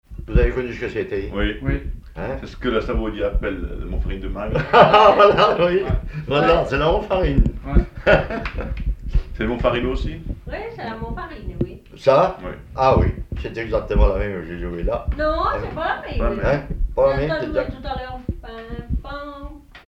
Localisation Magland
Catégorie Témoignage